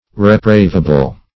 Reprevable \Re*prev"a*ble\ (r?-pr?v"?-b'l), a.